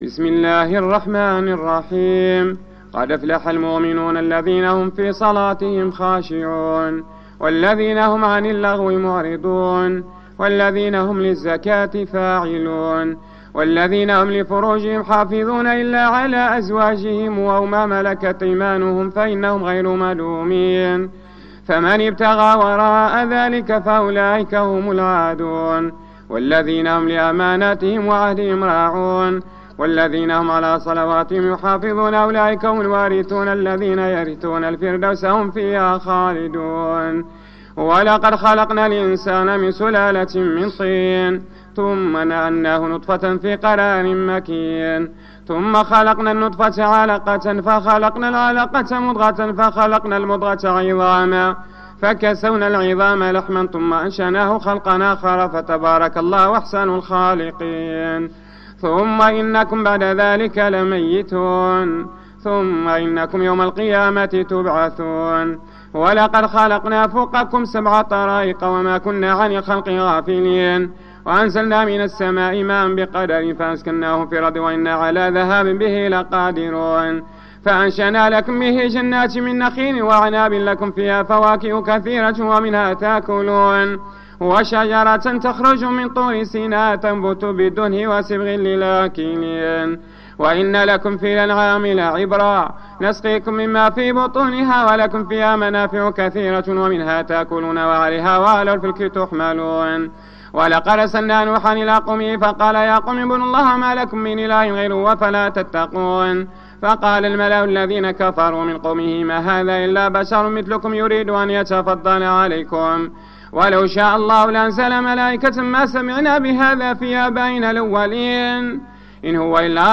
صلاة التراويخ بمسجد ابي بكر الصديق فقارة الزوى